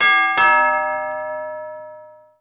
bell02.mp3